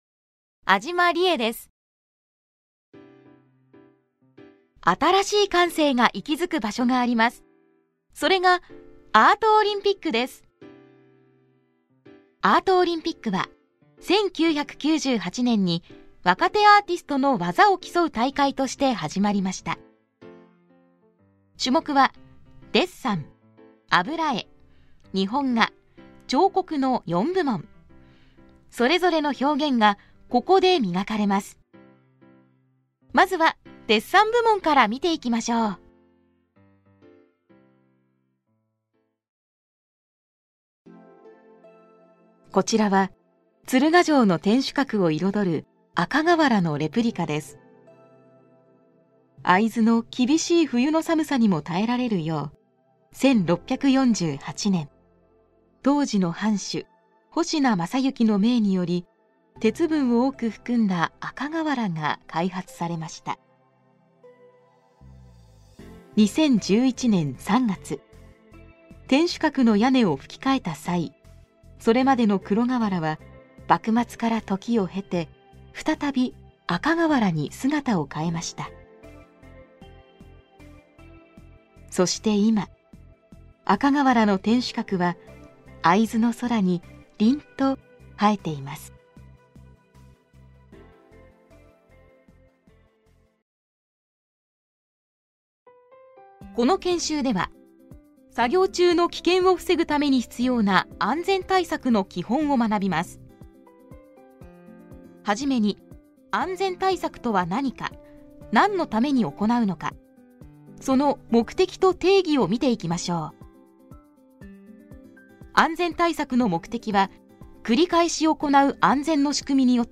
ボイスサンプル
• 明るいハキハキボイス
• 音域：高～中音
• 声の特徴：明るい、元気、爽やか